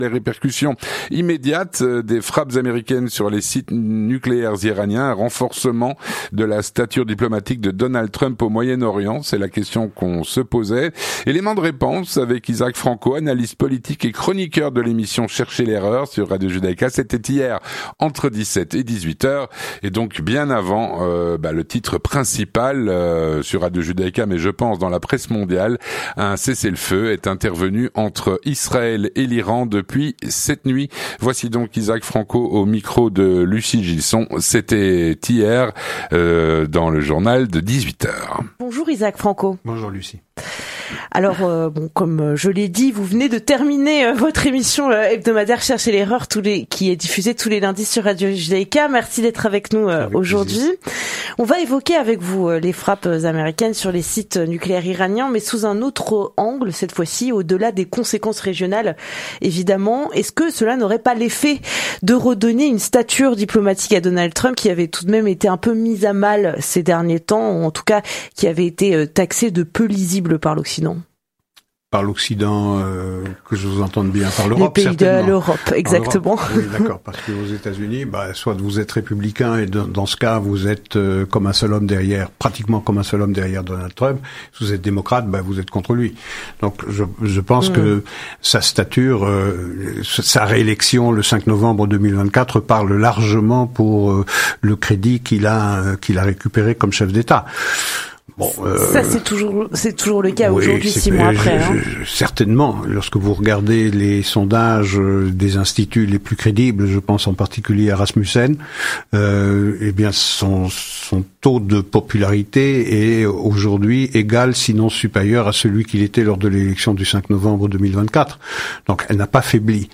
L'entretien du 18H - Les répercussions des frappes américaines sur les sites nucléaires iraniens.